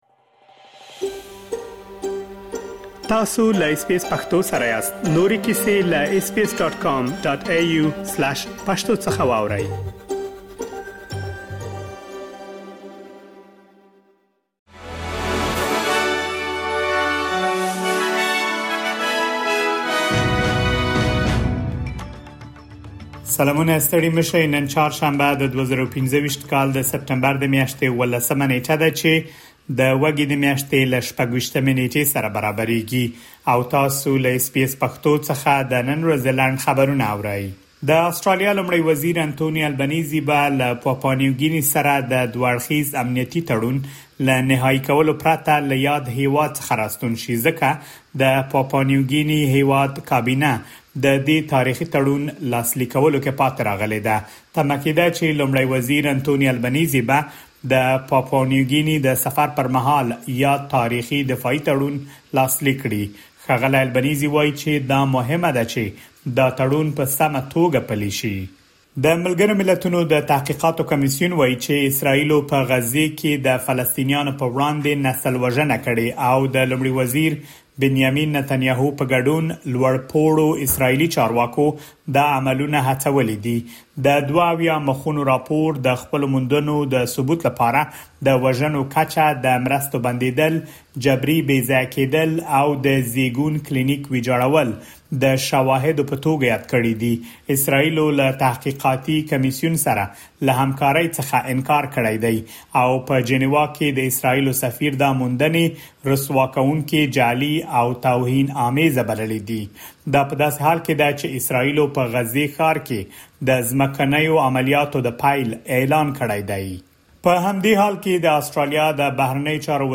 د اس بي اس پښتو د نن ورځې لنډ خبرونه |۱۷ سپټمبر ۲۰۲۵